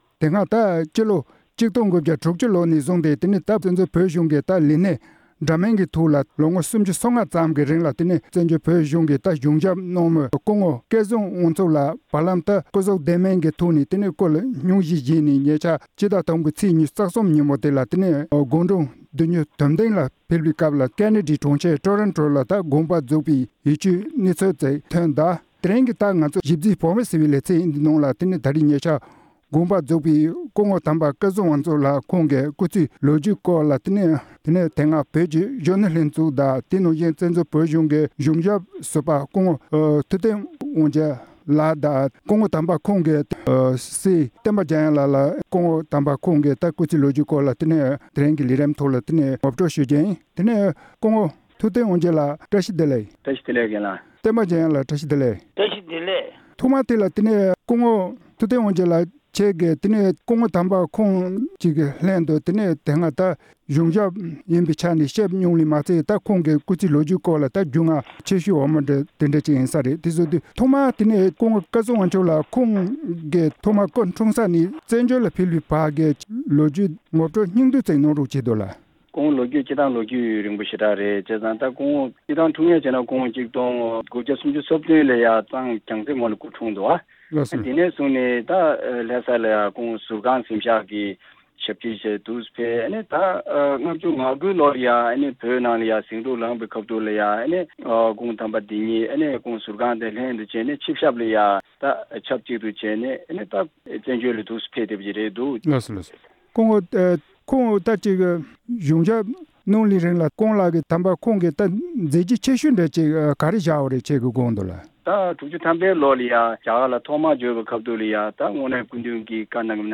འབྲེལ་ཡོད་མི་སྣར་གནས་འདྲི་ཞུས་པ་གསན་རོགས་གནང་།།